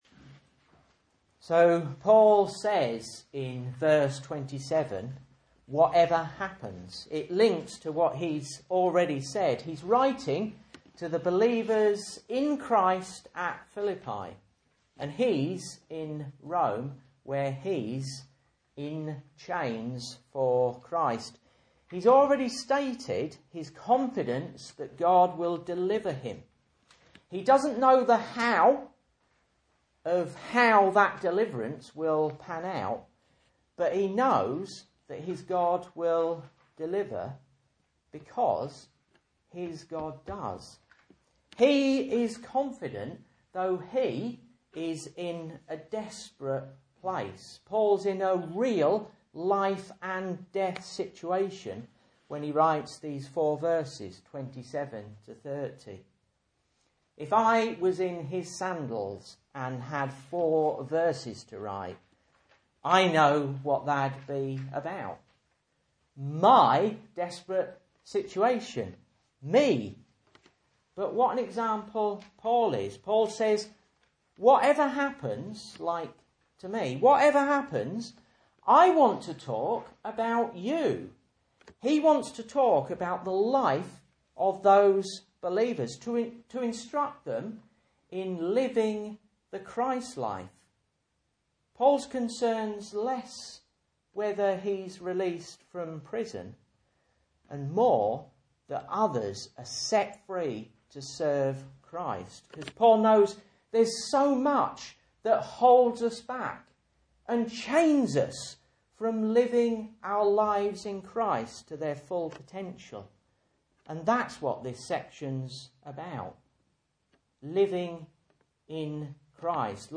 Message Scripture: Philippians 1:27-30 | Listen